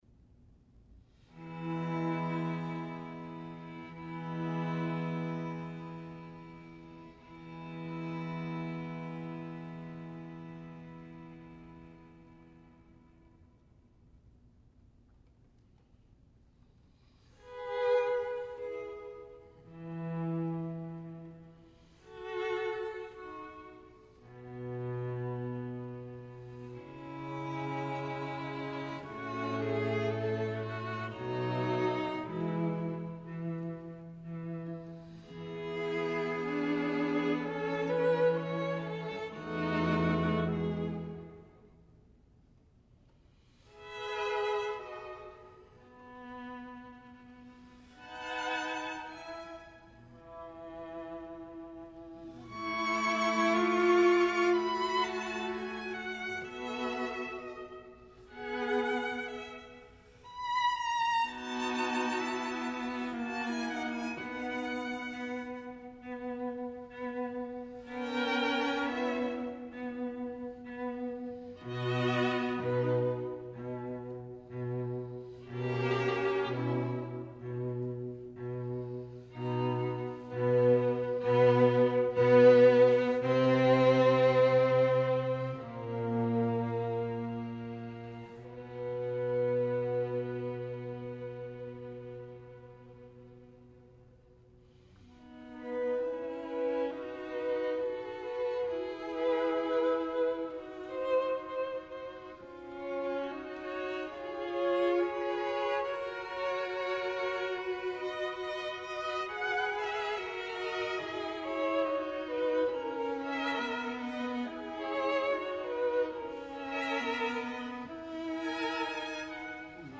Les Sept dernières Paroles du Christ, interprétée par le quatuor Guarneri